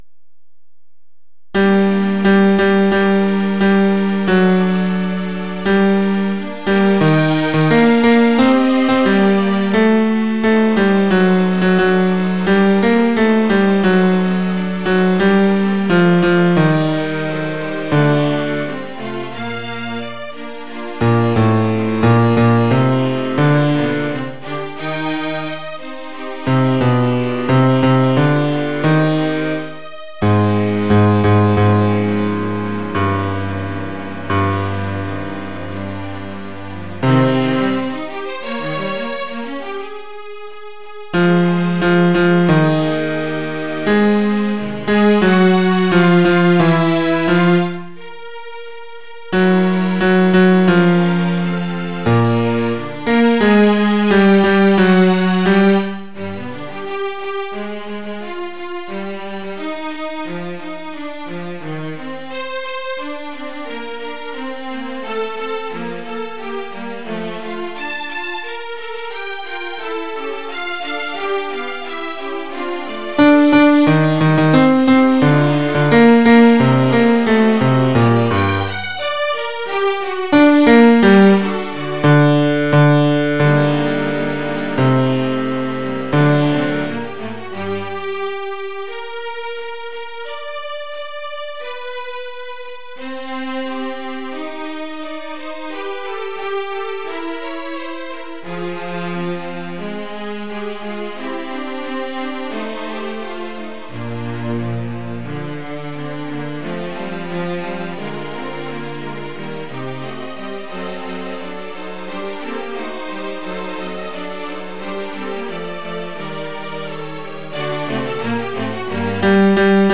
Les Choeurs de Paris 13
domine_bas.mp3